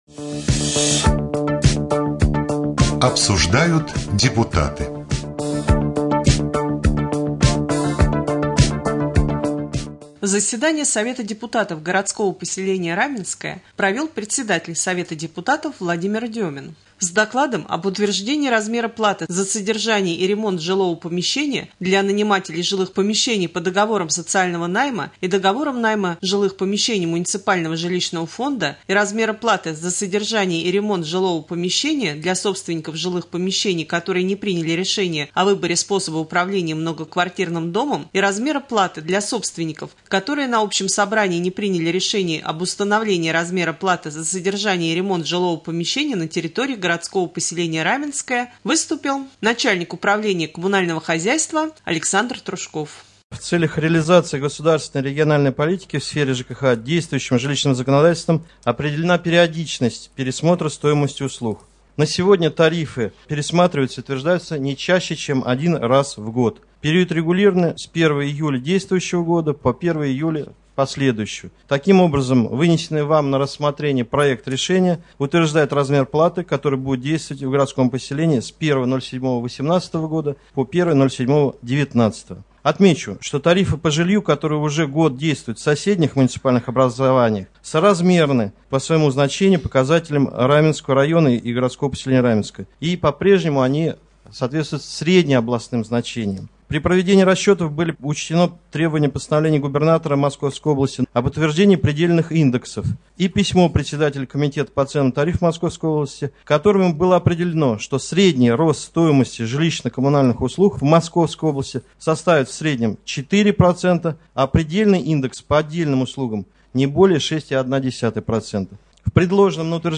16.07.2018г. в эфире Раменского радио - РамМедиа - Раменский муниципальный округ - Раменское